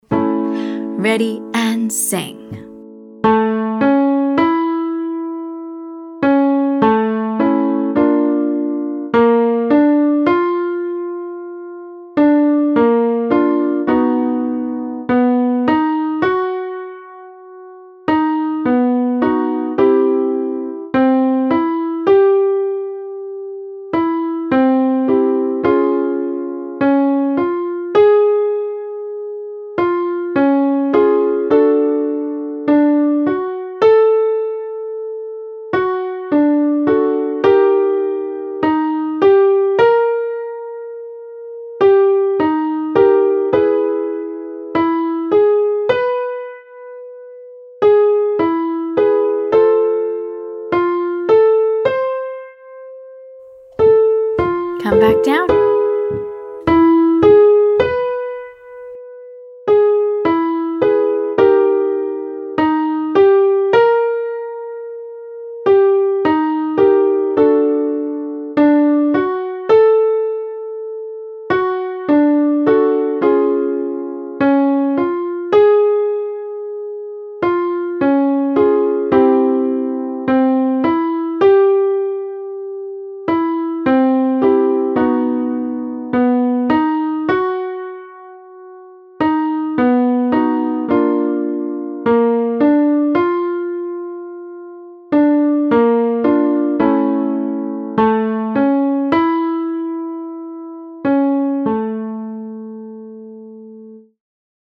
Same as before, we’re sustaining the top pitch.
Exercise 2: Sustained WHEN/WEH 135—31